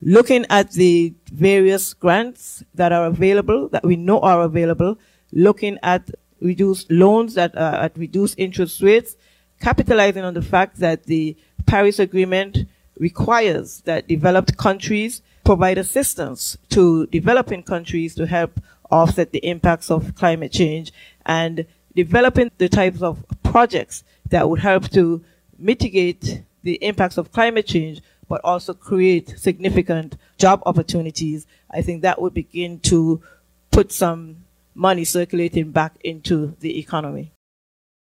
This was posed at a press conference hosted by the Party on Aug. 27th. Leader of the party (Candidate for Nevis #4, St. James’ Parish), Dr. Janice Daniel-Hodge, went into detail concerning a plan to stir economic activity on Nevis, given the present circumstances: